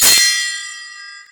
blackberry-message_24682.mp3